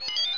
1 channel
sound_rmmenudrop.mp3